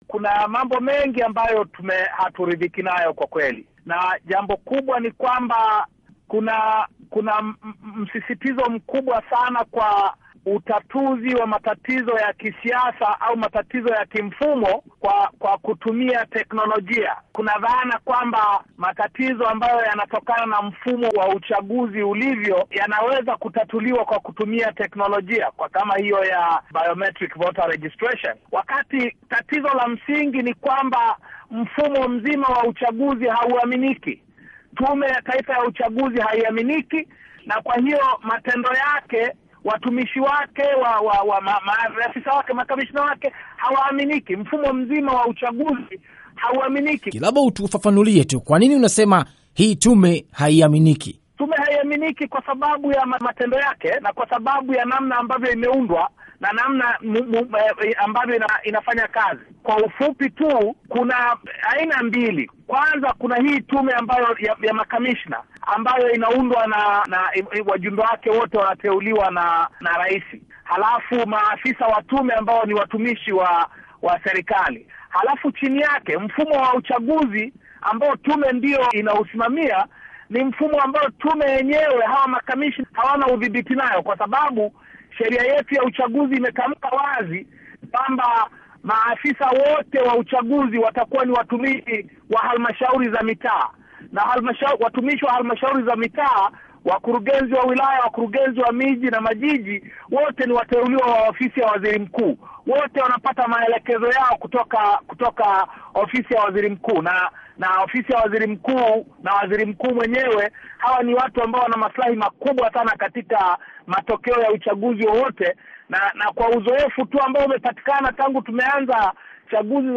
Mahojiano na Tundu Lissu.